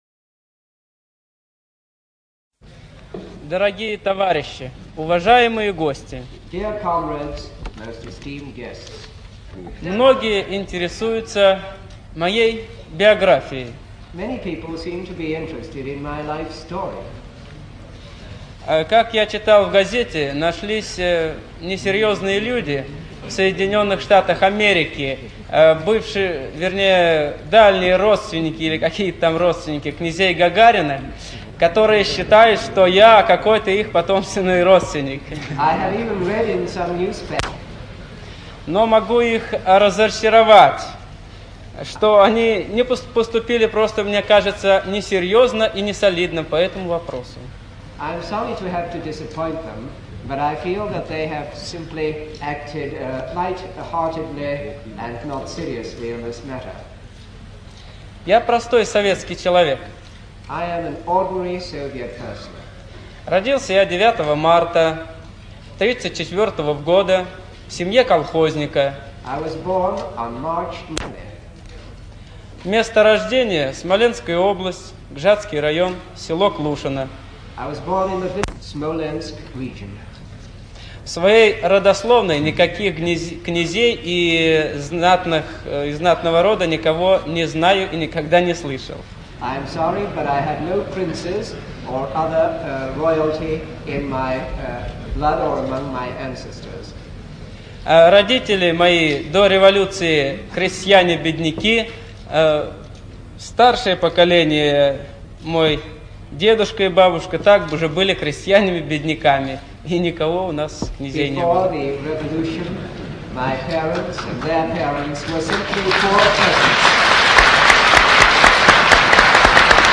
ЧитаетАвтор
ЖанрДокументальные фонограммы
Гагарин Ю - Автобиография (Автор)(preview).mp3